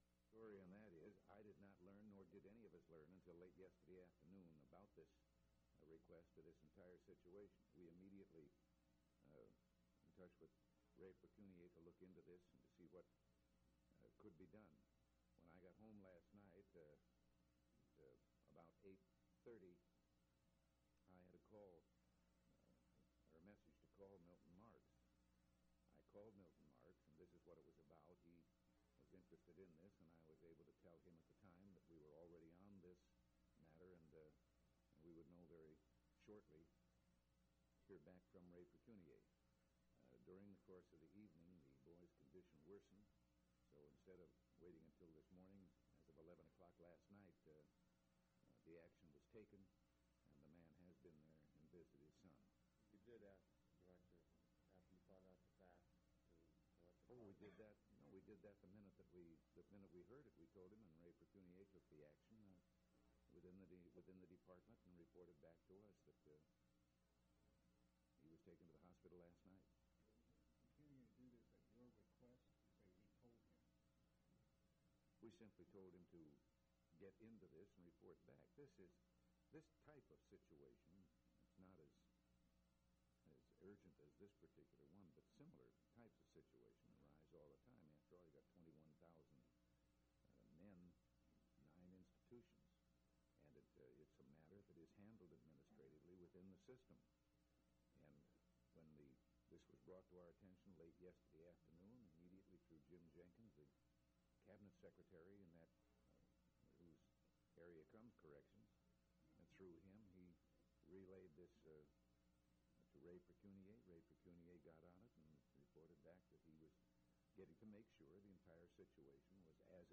Governor Ronald Reagan News Conference